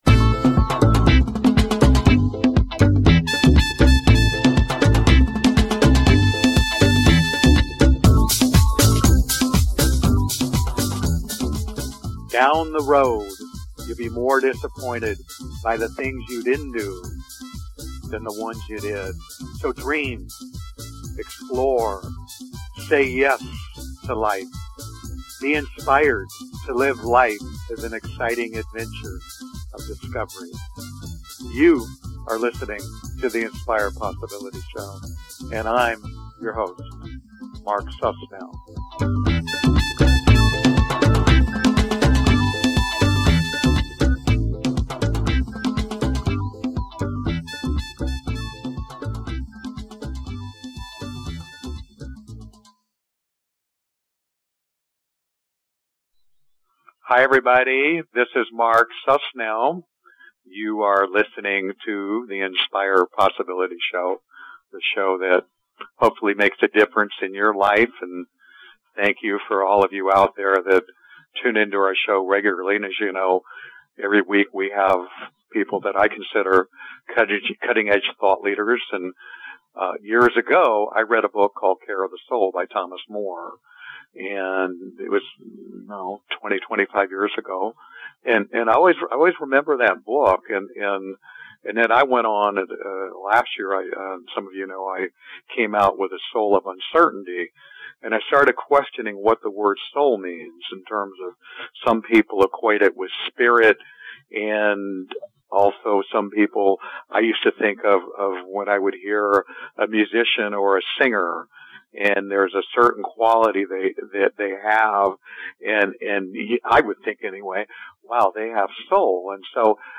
Talk Show Episode, Audio Podcast, Inspire Possibility Show and Thomas Moore, Author of Care of the Soul on , show guests , about Thomas Moore,Care of the Soul, categorized as Entertainment,Health & Lifestyle,Kids & Family,Paranormal,Philosophy,Psychology,Self Help,Spiritual
Listen to this enlightening and uplifting conversation with Thomas Moore, who is the author of Care of the Soul, the number one bestselling classic on living a soul-based life.